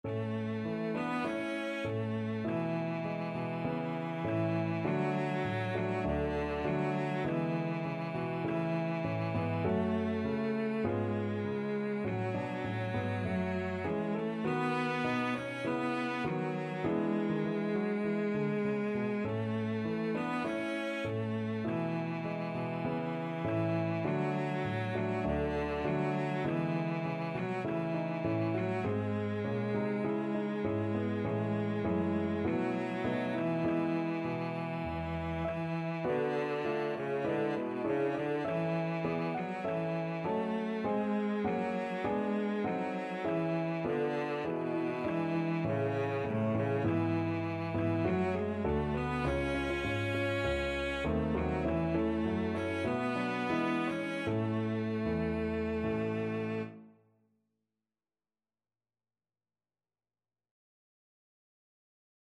Traditional Trad. Amhran na bhFiann (A Soldier's Song) (Irish National Anthem) Cello version
Cello
A3-Db5
A major (Sounding Pitch) (View more A major Music for Cello )
4/4 (View more 4/4 Music)
Traditional (View more Traditional Cello Music)